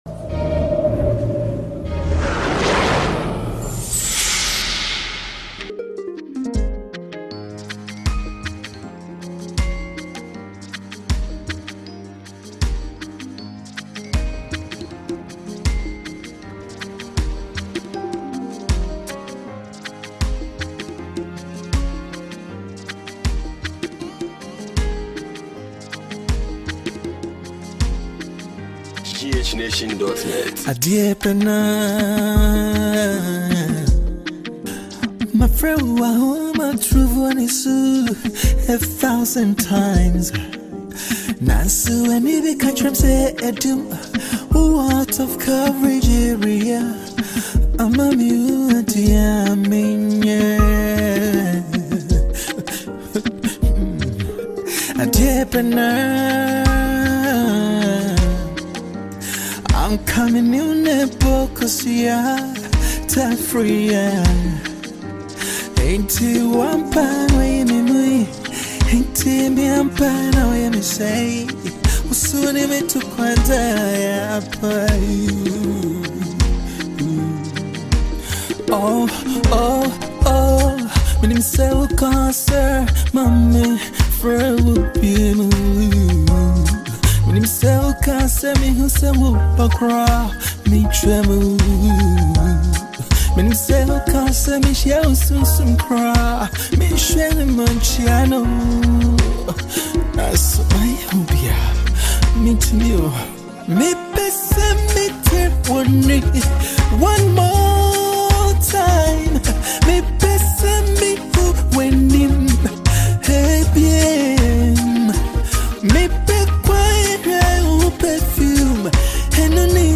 traditional song